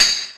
Tambourine_2.wav